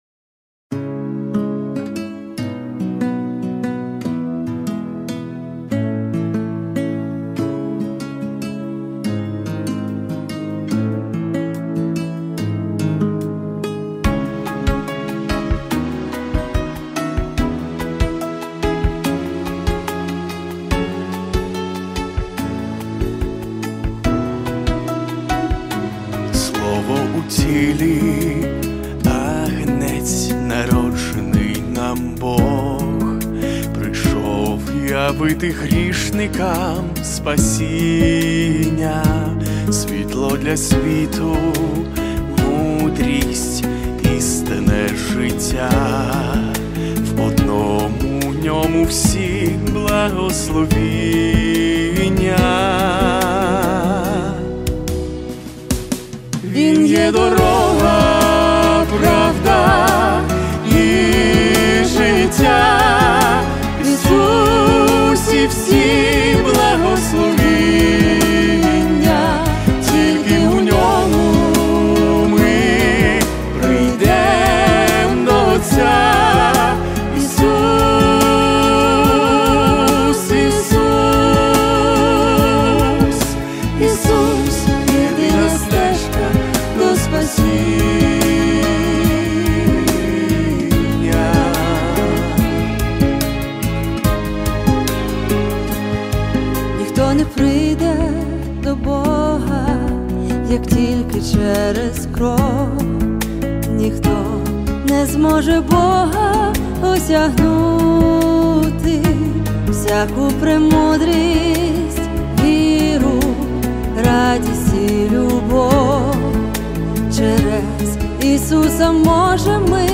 351 просмотр 394 прослушивания 80 скачиваний BPM: 143